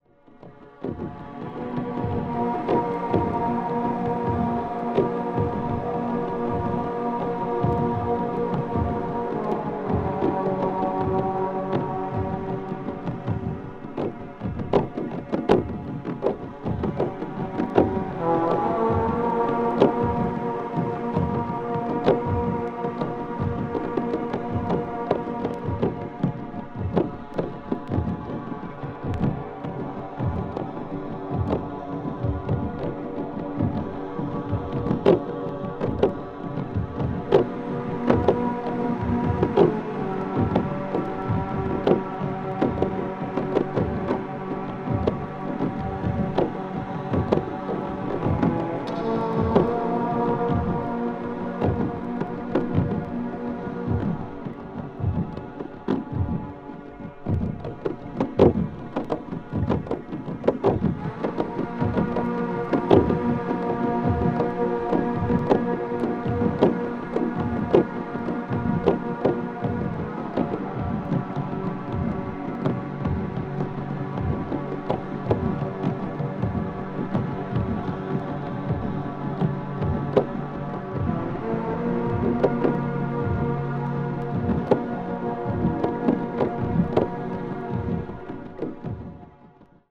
media : EX+/EX+(some slightly noises.)
ambient   avant garde   electronic   experimental   minimal